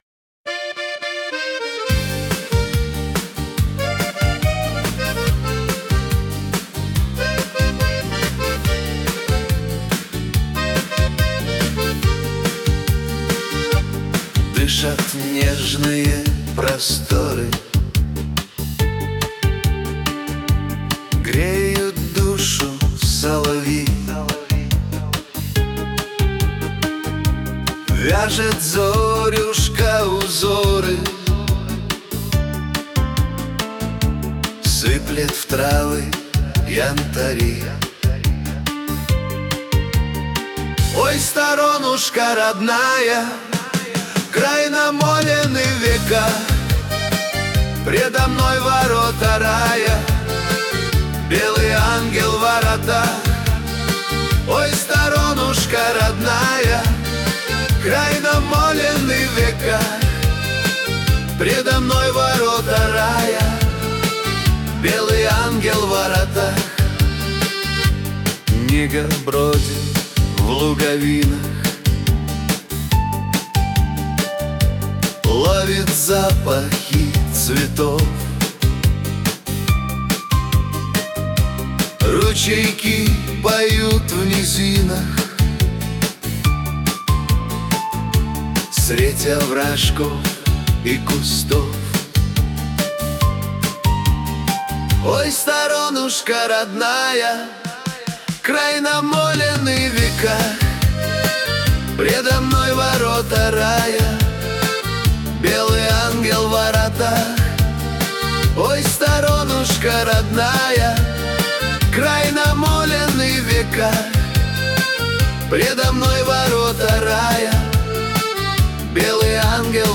Аудиостихотворение